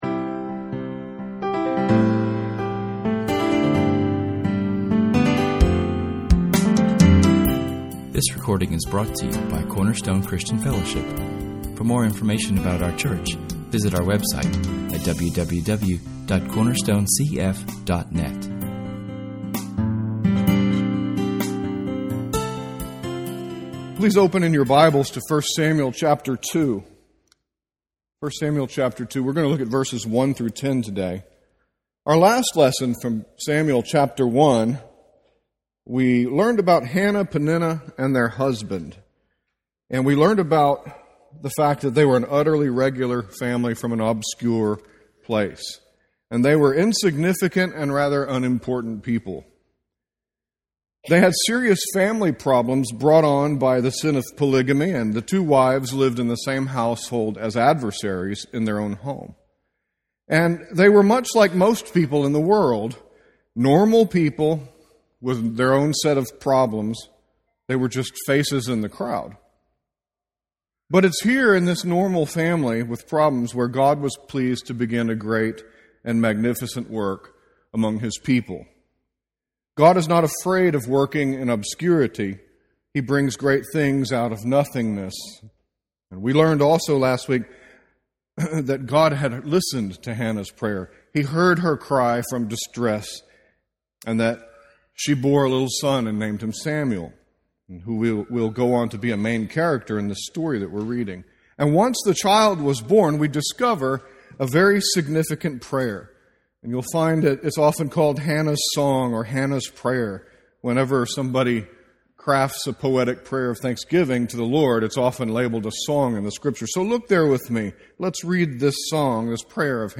This sermon investigates the great reversal of providence Hannah experienced as her strong God and deliverer turned her circumstances from cursed to blessed.